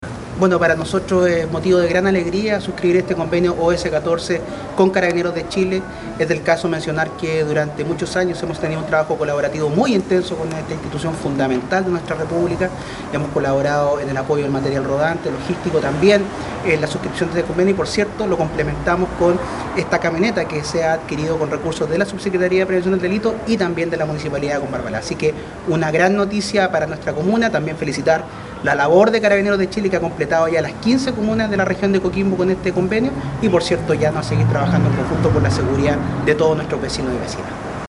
Por su parte el Alcalde de Combarbalá Pedro Castillo señaló que:
Alc-Pedro-Castillo-en-firma-OS14.mp3